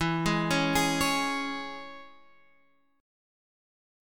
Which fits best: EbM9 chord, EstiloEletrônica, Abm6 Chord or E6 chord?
E6 chord